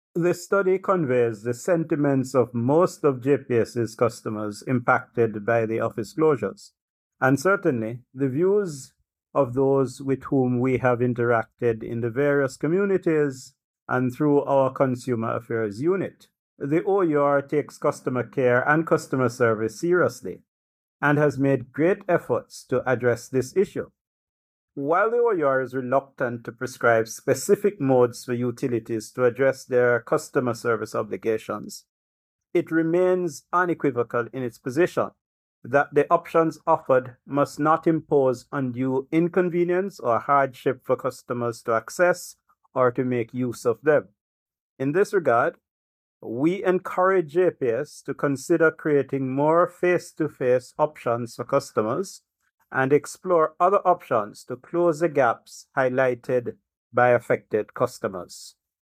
OUR-DG-on-JPS-Office-Closure.mp3